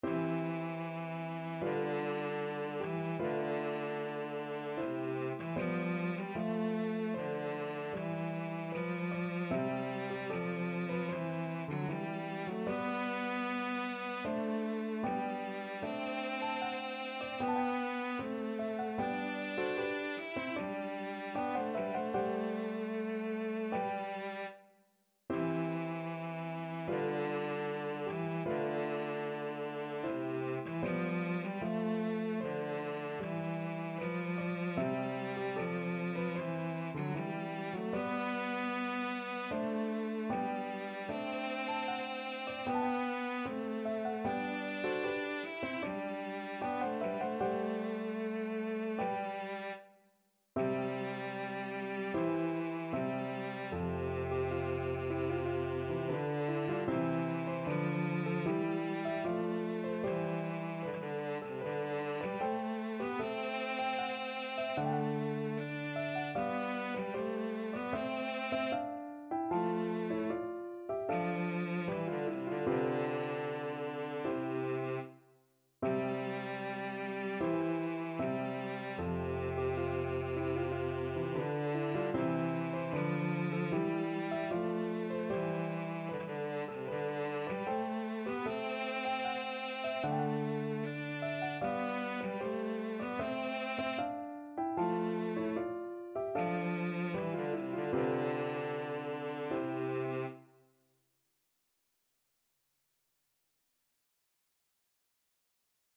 4/4 (View more 4/4 Music)
Andante =76
Classical (View more Classical Cello Music)